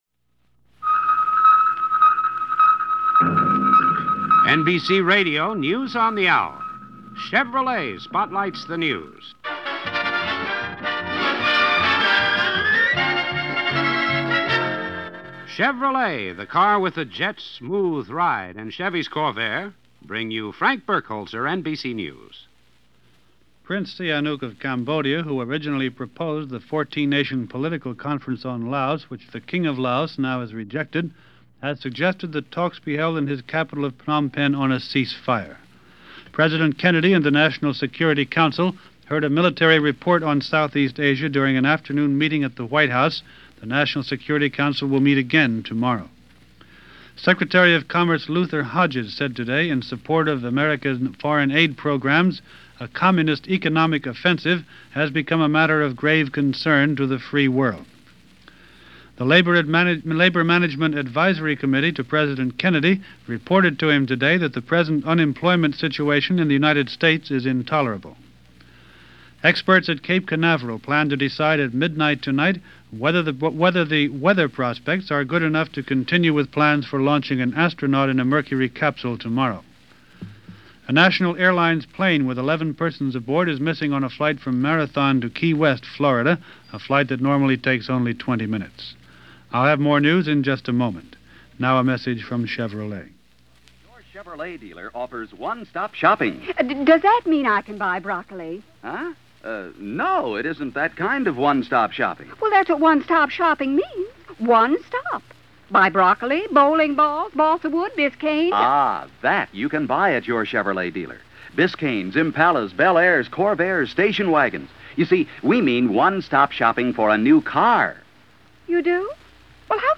NBC Radio – News On The Hour- May 1, 1961 – Gordon Skene Sound Collection –
And aside from the continuing story of Southeast Asia that’s a little of what went on this May 1st in 1961, as reported over NBC Radio’s News On The Hour.